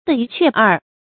的一確二 注音： ˙ㄉㄜ ㄧ ㄑㄩㄝˋ ㄦˋ 讀音讀法： 意思解釋： 形容明明白白，確確實實。